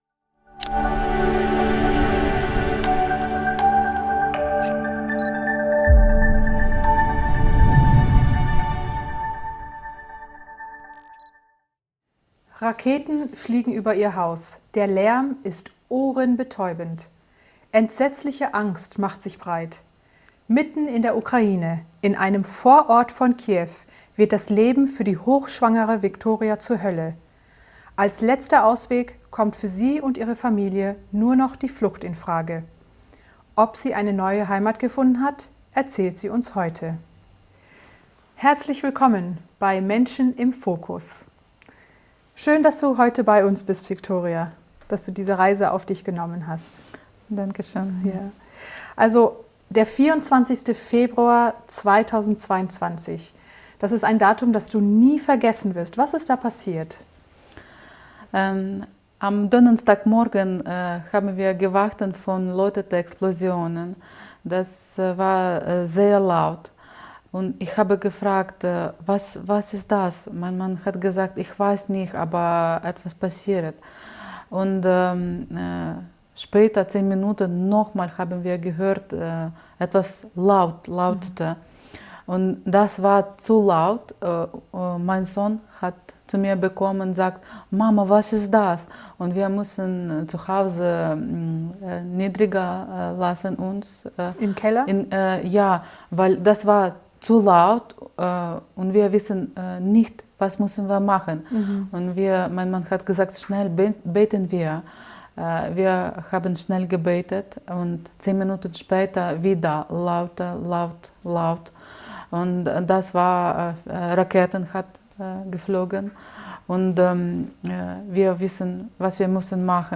Als letzter Ausweg kommt für sie und ihre Familie nur noch die Flucht in Frage. Ob sie eine neue Heimat gefunden hat, davon erzählt sie in diesem Interview.